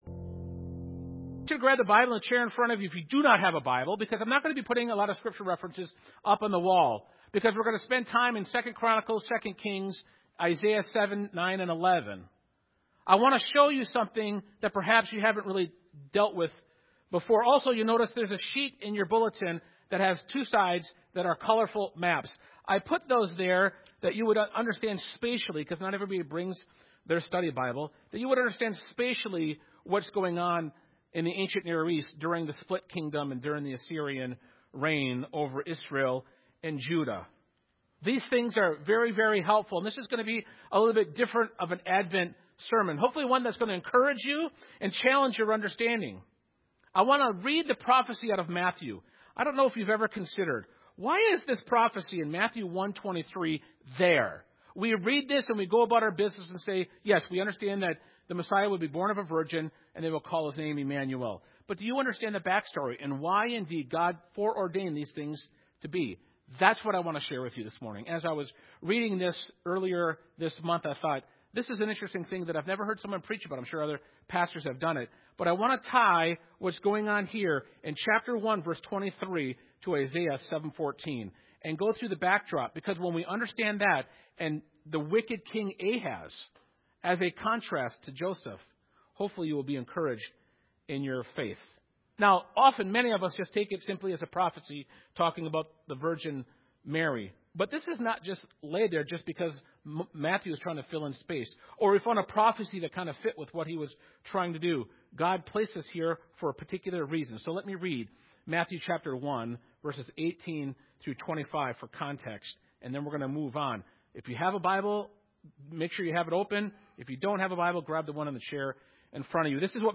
Trust God When He Speaks – Mount of Olives Baptist Church